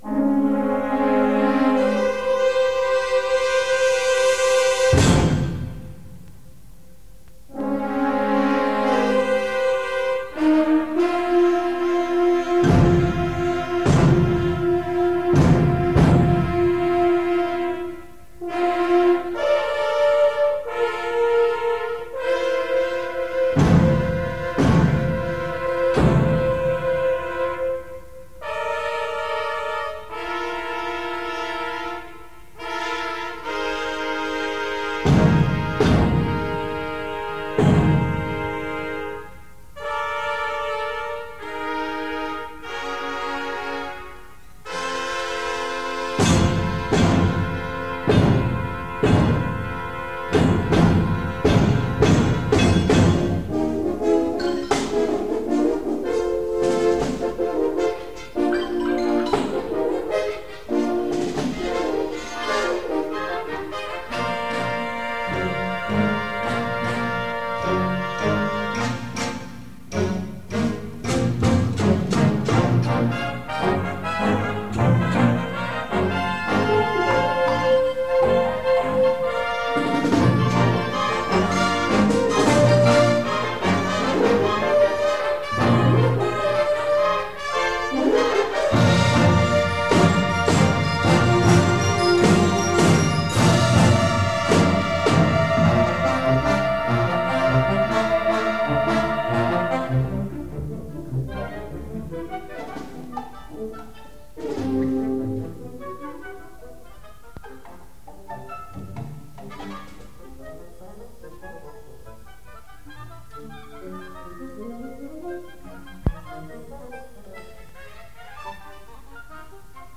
(Live Performance, 1973